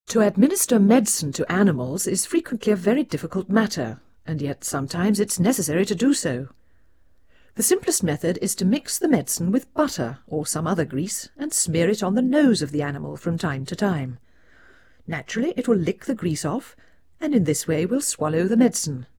Play 10 dB SNR Noise Mono
Noise in-phase
speech audibly distorted